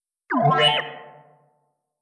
spot-move.wav